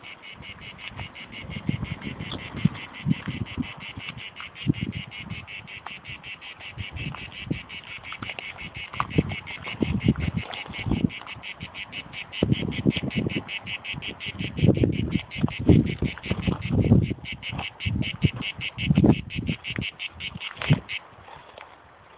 Cigale grise Cicada orni
La première Cigale grise de l'année